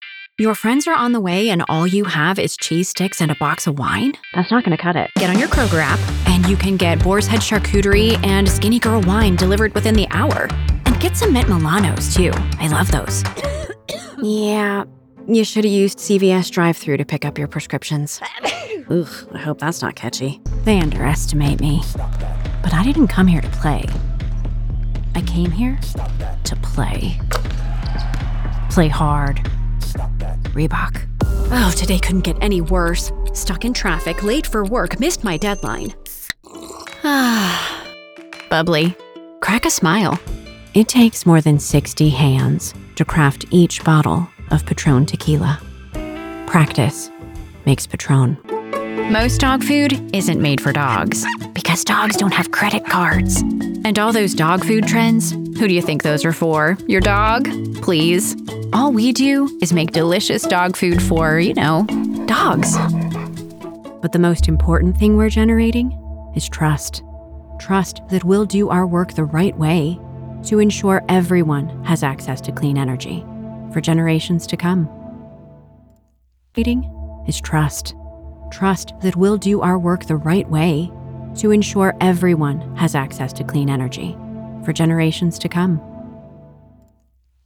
COMMERCIAL DEMO
My voice is mid-high pitched, naturally Mid-West Gen X, but years of vocal training have allowed me to voice characters from kids to crones.